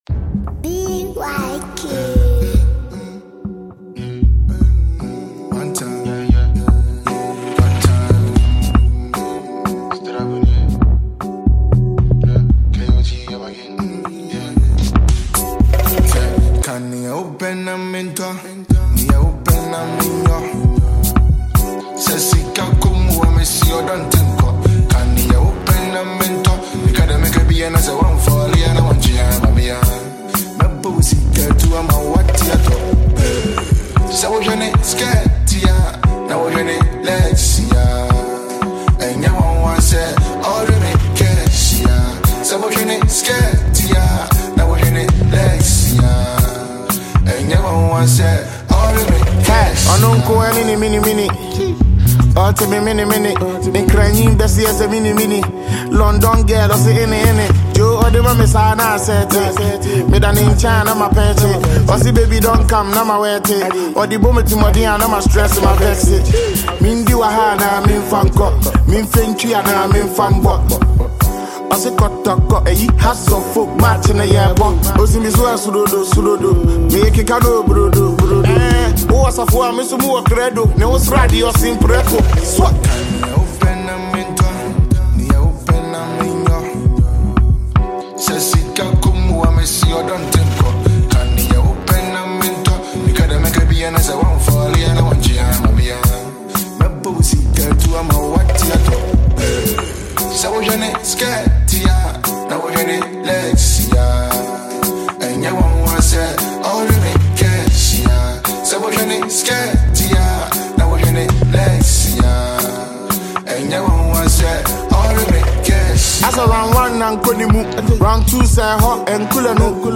is a catchy and upbeat track
With its catchy hooks and infectious beats
hip-hop